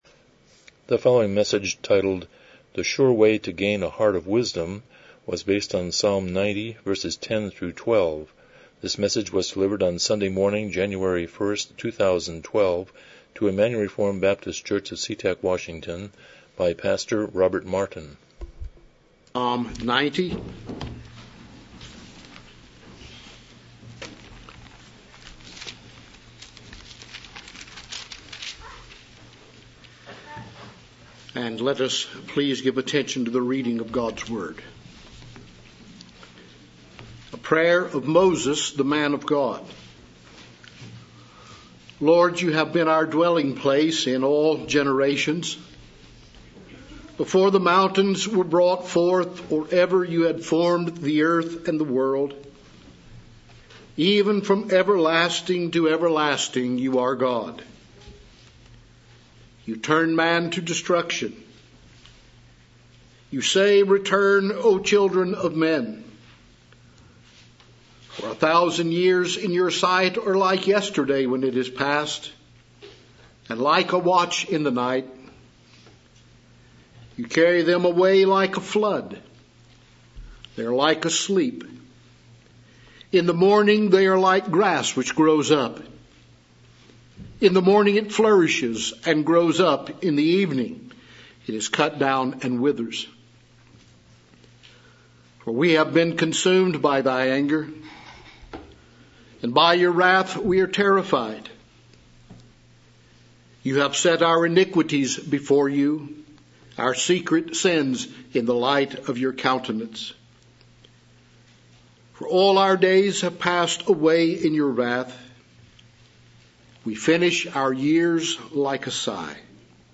Passage: Psalm 90:10-12 Service Type: Morning Worship